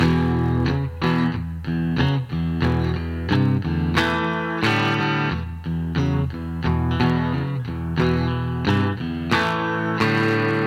E吉他节奏 90 BPM E
描述：90 BPM，有很大的空间可供扭曲。E和弦的节奏。约有10分贝的净空，所以你有空间来提升它。
Tag: 90 bpm Pop Loops Guitar Electric Loops 1.80 MB wav Key : E